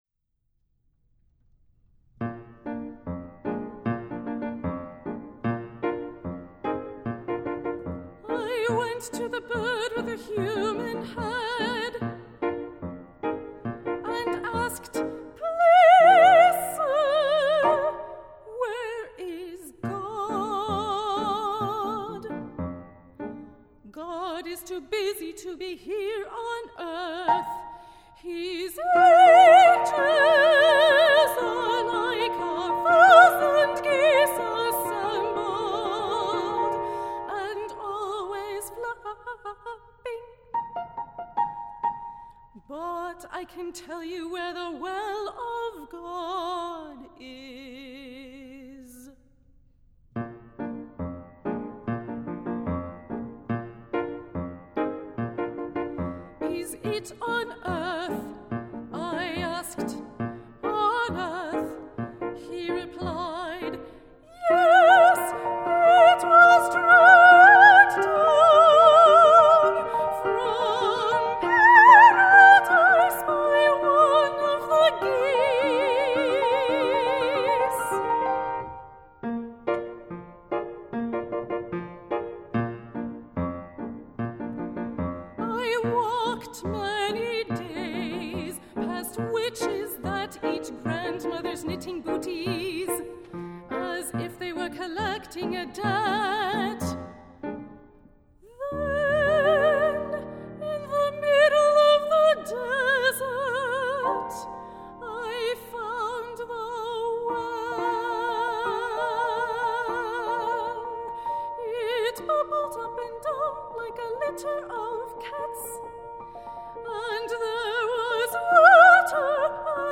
for High Voice and Piano (2013)